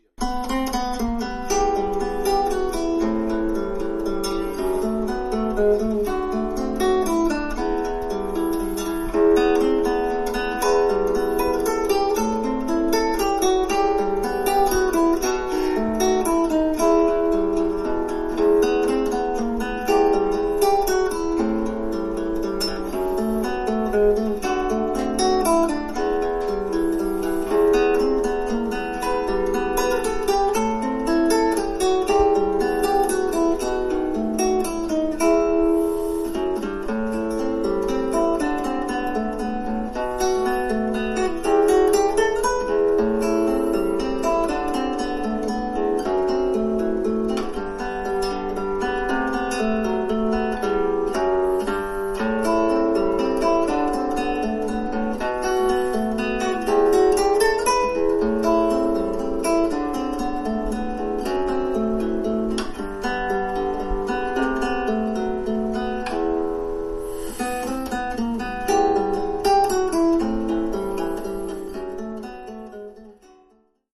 Oeuvre pour guitare solo.